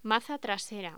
Locución: Maza trasera
voz